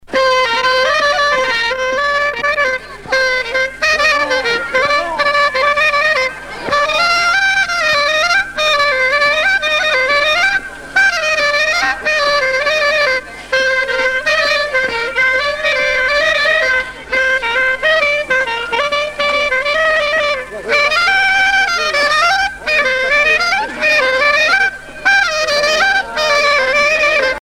danse
Pièce musicale éditée